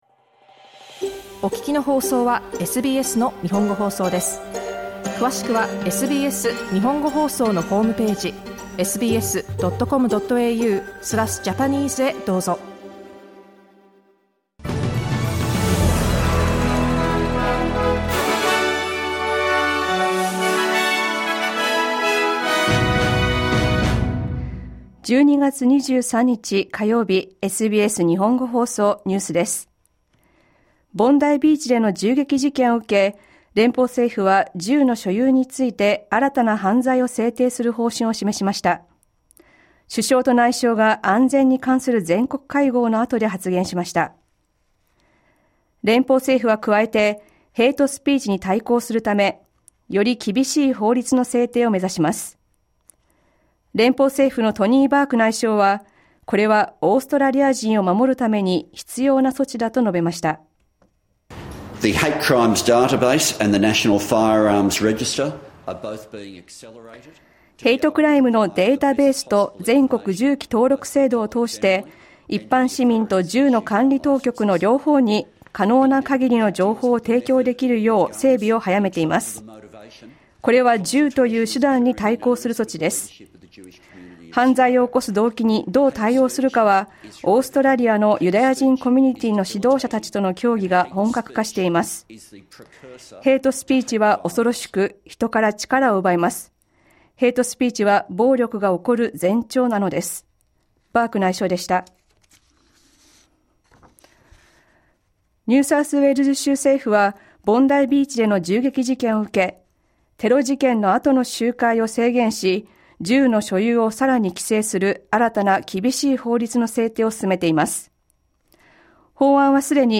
News from today's live program (1-2pm).